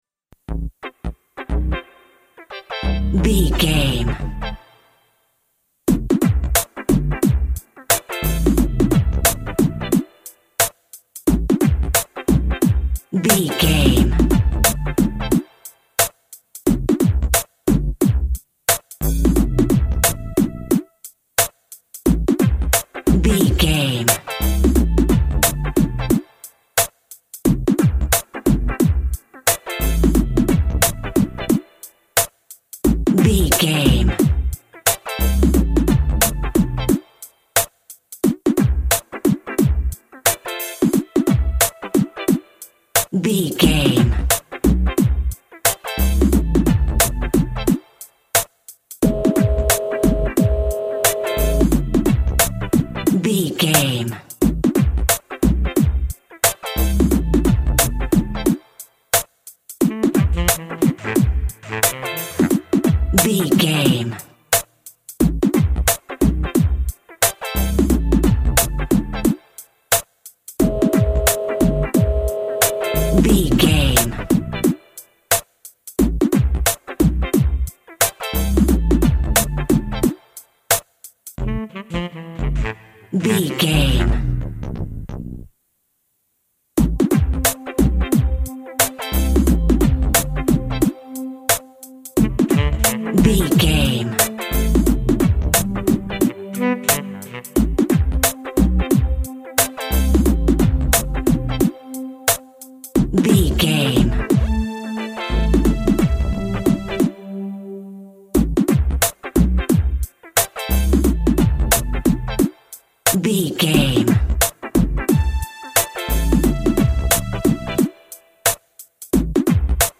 Aeolian/Minor
synth lead
synth bass
hip hop synths
electronics